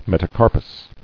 [met·a·car·pus]